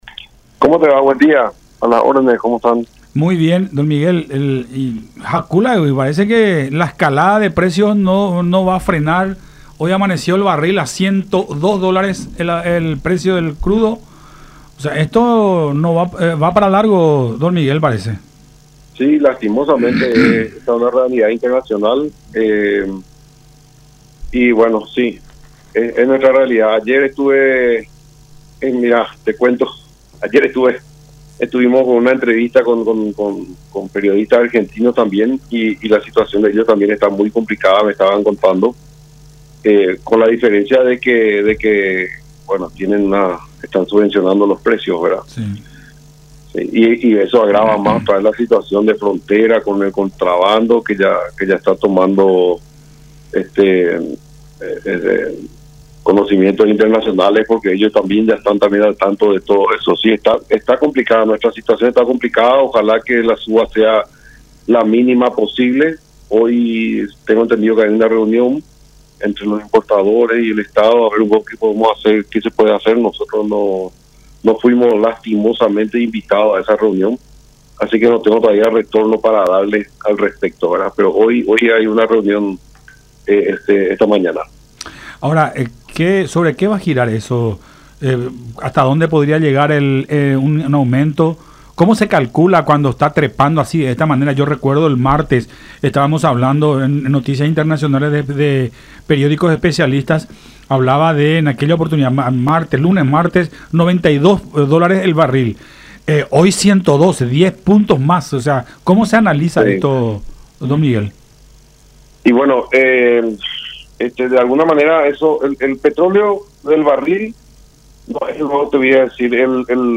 en charla con Todas Las Voces por La Unión, en alusión al precio del barril del petróleo, que durante esta jornada ha superado los 100 dólares por unidad, el mayor valor en ocho años.